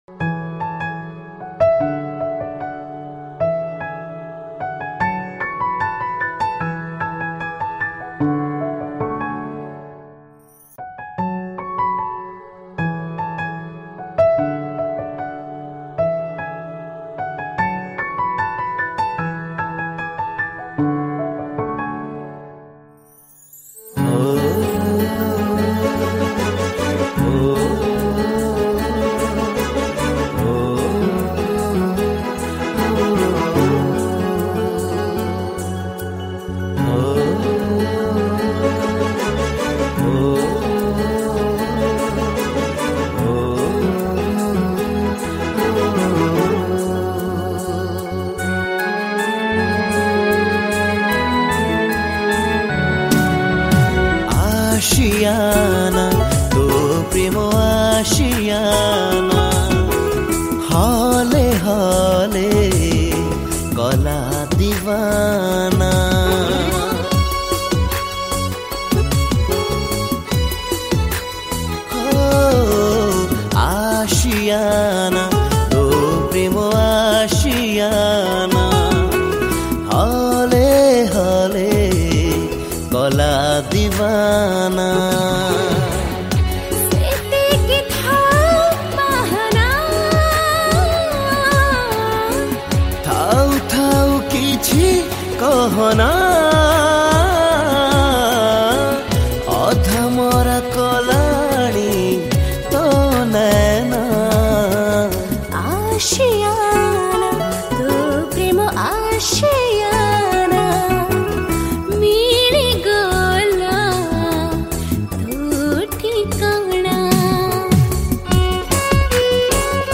Odia New Romantic Song